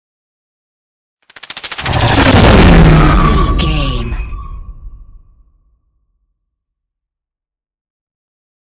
Scifi passby whoosh
Sound Effects
futuristic
high tech
pass by
vehicle